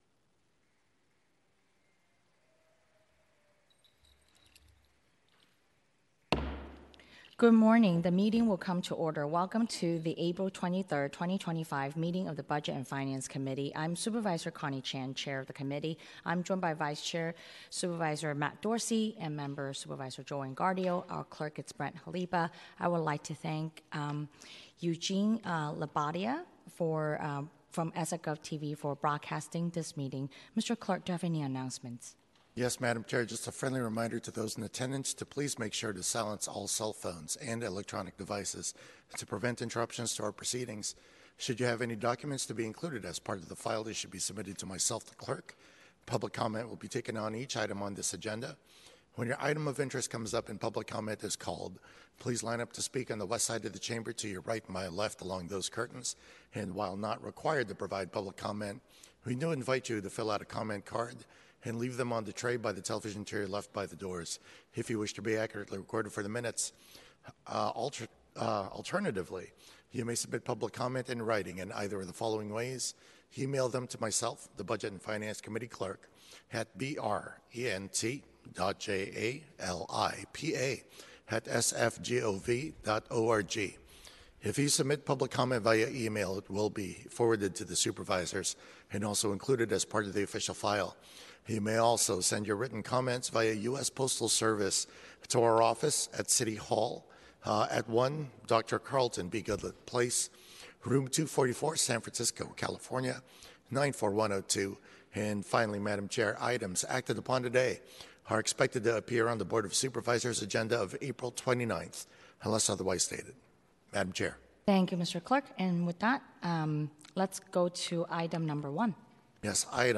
BOS Budget and Finance Committee - Regular Meeting - Apr 23, 2025